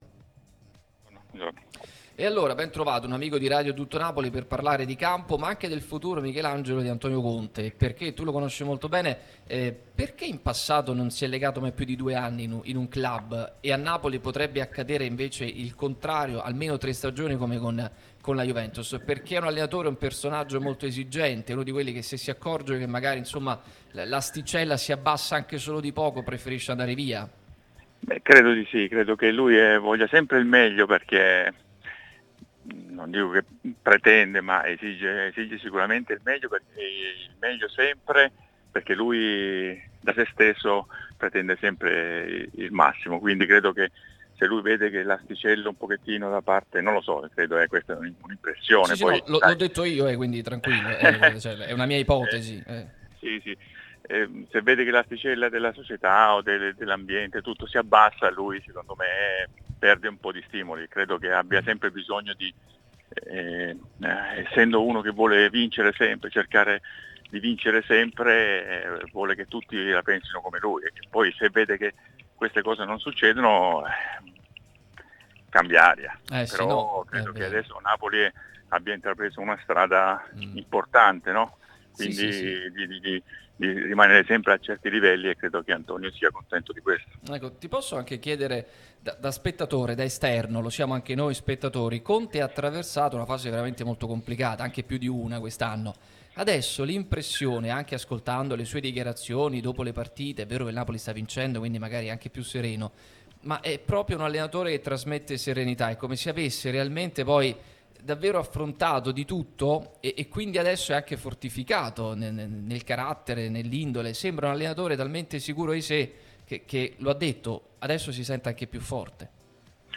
Michelangelo Rampulla è intervenuto durante Pausa Caffè su Radio Tutto Napoli , prima radio tematica sul Napoli